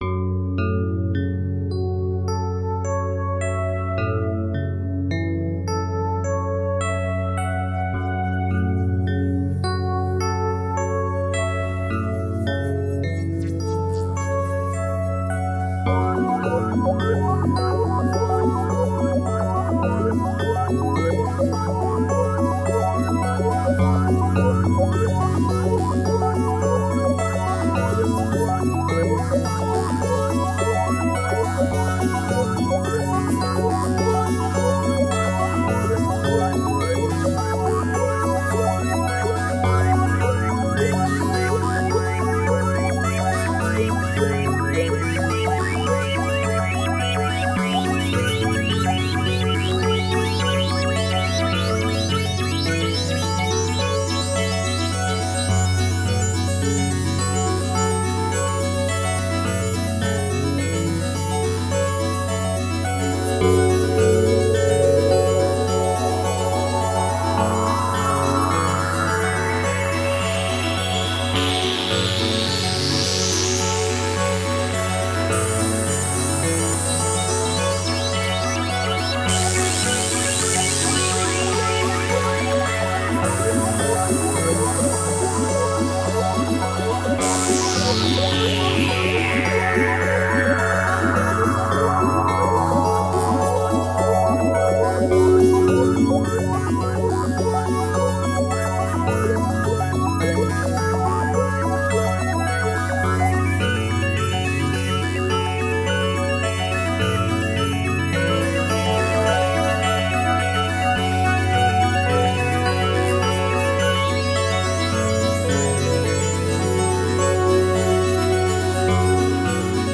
Composed electronic music contrasted with ambient nature.
Tagged as: Electronica, Other